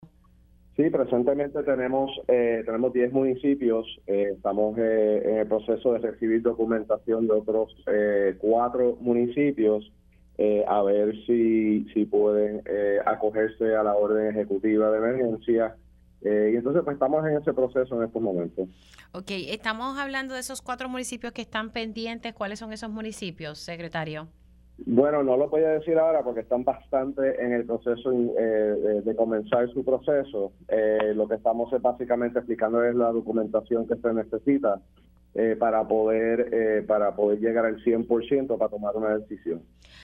El secretario de Seguridad Pública, General Arturo Garffer indicó en Pega’os en la Mañana que podría aumentar a unos 14 municipios con daños provocados por las lluvias torrenciales que han afectado a la isla desde el 19 de abril.